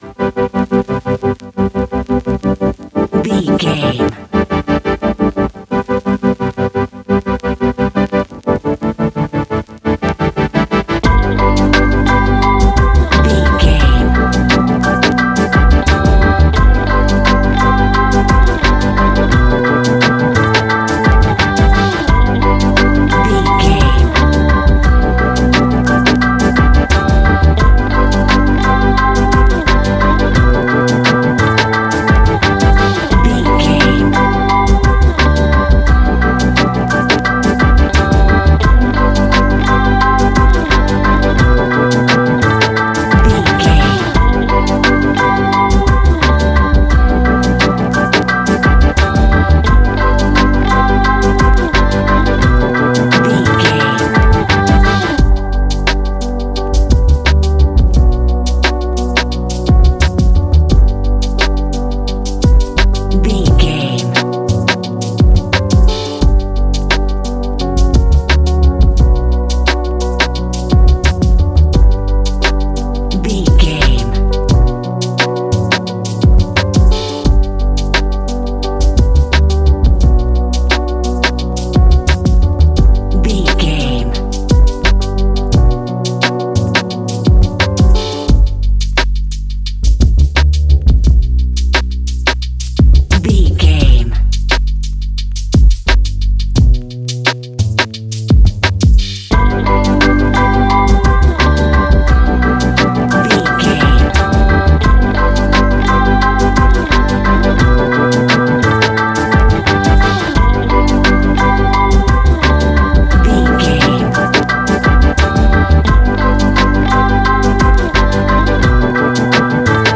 Ionian/Major
laid back
Lounge
sparse
new age
chilled electronica
ambient
atmospheric